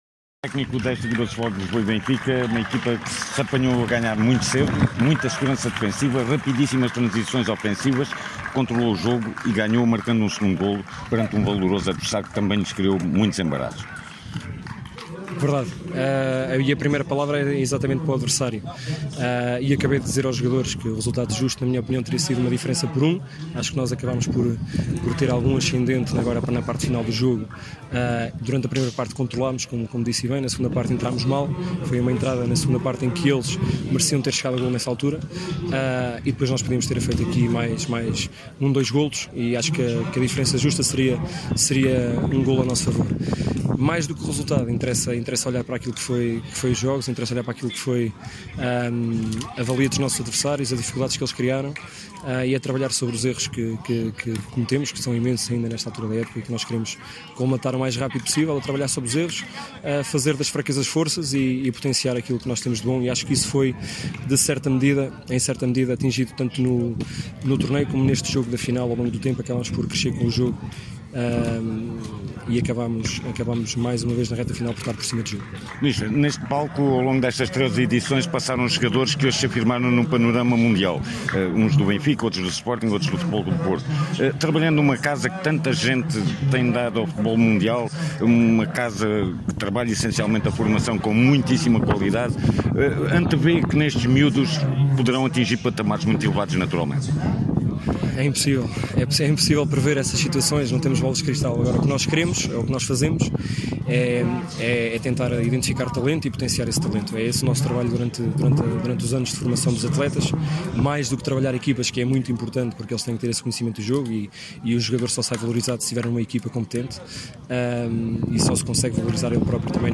No final fomos ouvir os técnicos de ambos os conjuntos: